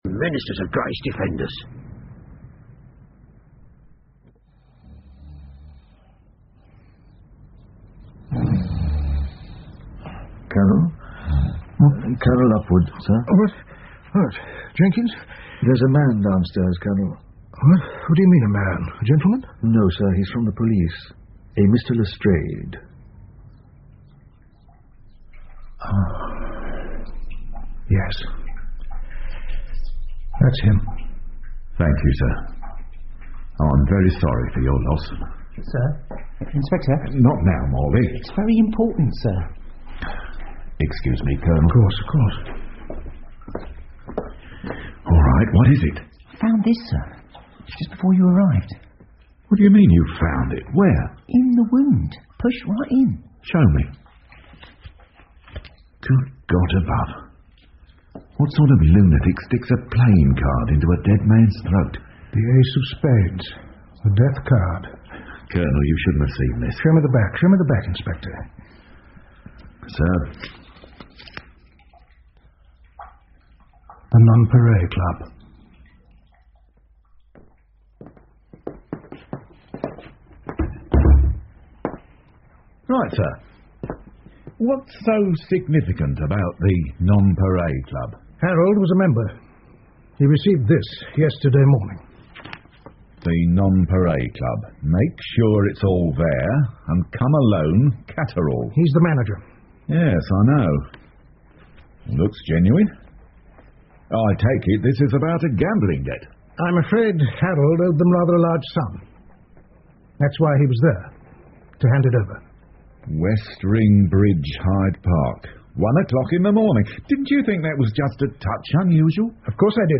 福尔摩斯广播剧 The Striking Success Of Miss Franny Blossom 2 听力文件下载—在线英语听力室